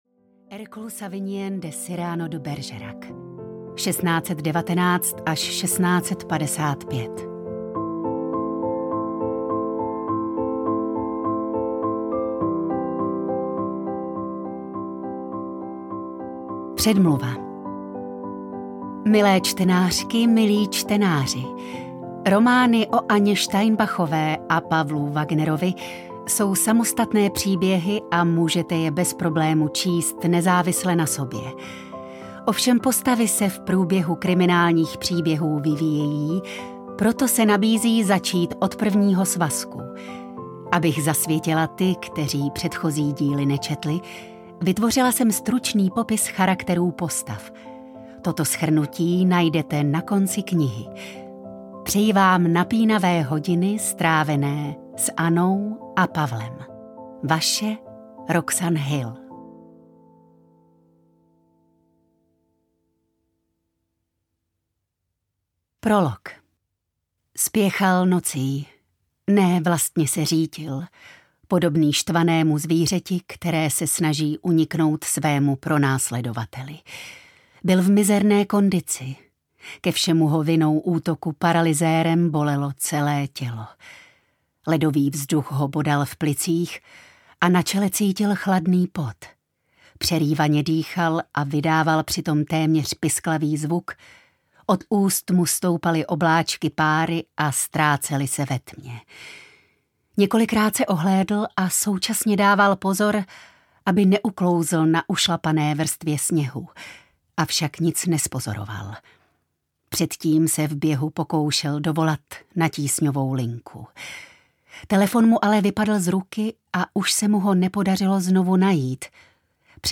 Duše mrtvých nemluví audiokniha
Ukázka z knihy